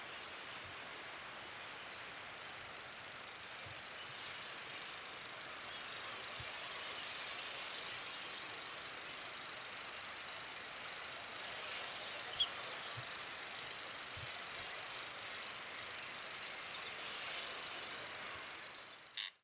Art: Gresshoppesanger (Locustella naevia)
Engelsk navn: Common Grasshopper Warbler
Lyd fra gresshoppesanger